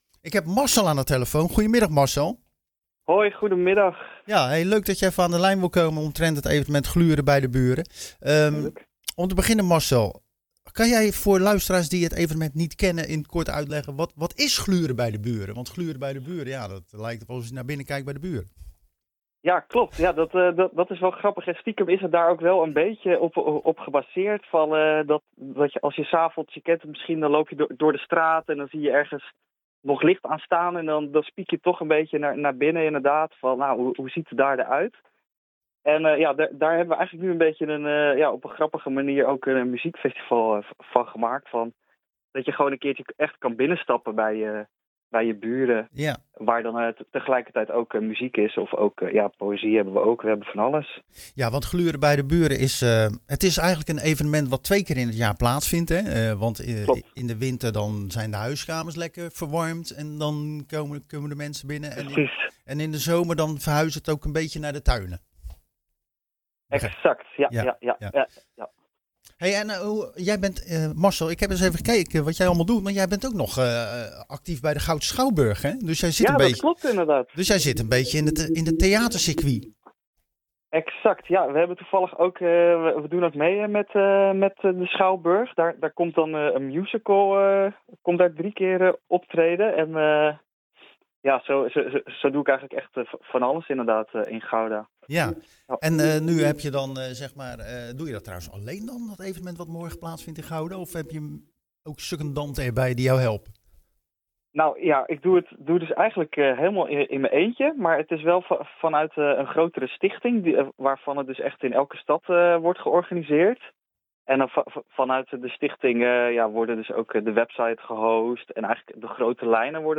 De wintereditie vindt plaats in 22 steden.